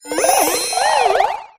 eldegoss_ambient.ogg